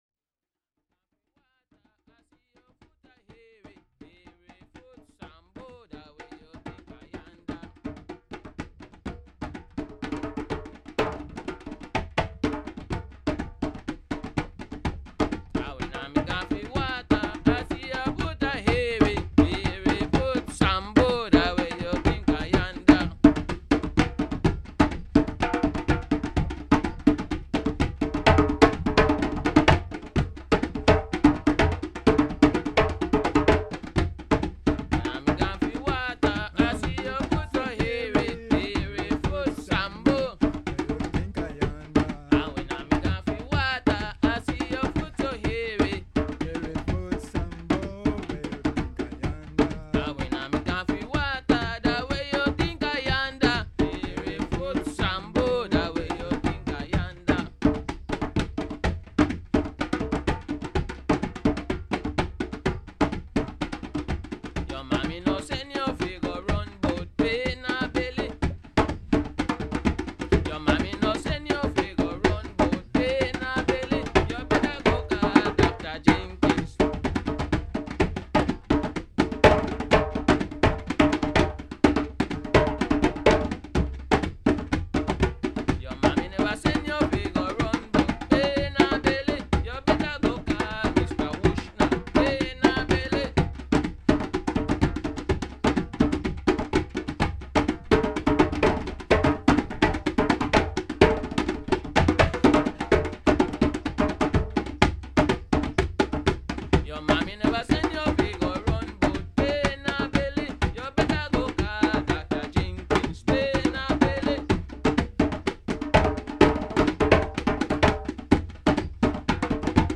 traditional song